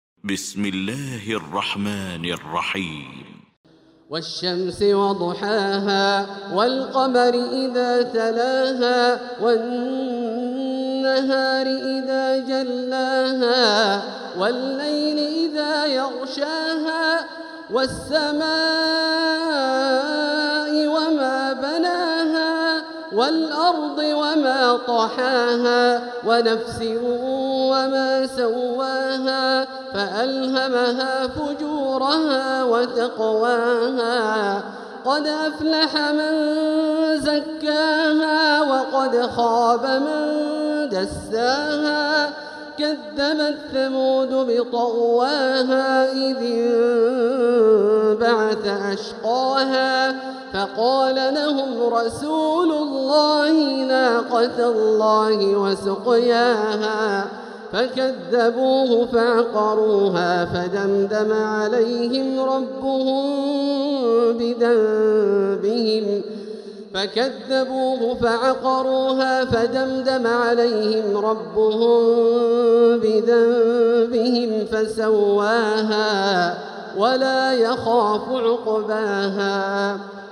المكان: المسجد الحرام الشيخ: فضيلة الشيخ عبدالله الجهني فضيلة الشيخ عبدالله الجهني الشمس